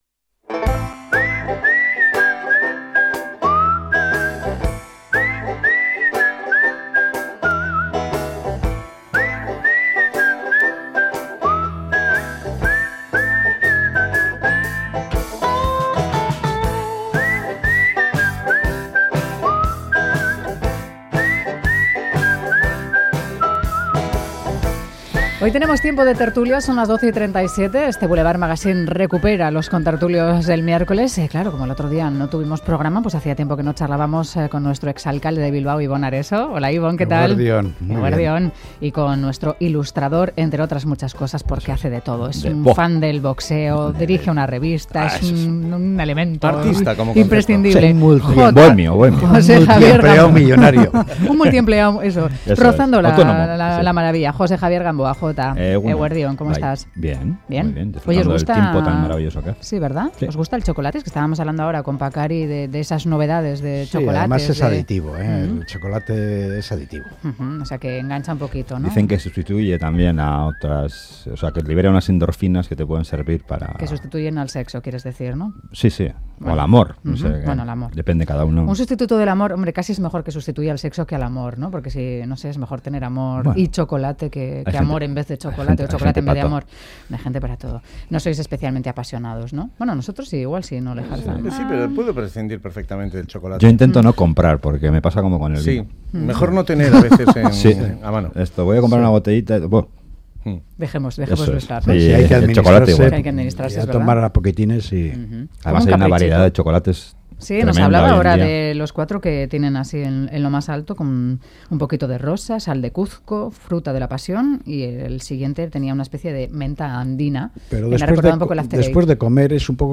Audio: Reflexionamos en la tertulia semanal sobre el debate que se abierto en Alemania ante los menores que llegan casados en sus países de orígen.